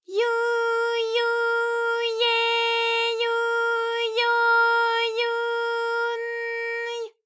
ALYS-DB-001-JPN - First Japanese UTAU vocal library of ALYS.
yu_yu_ye_yu_yo_yu_n_y.wav